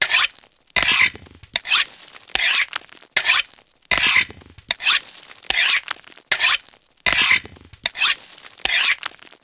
sharpen.ogg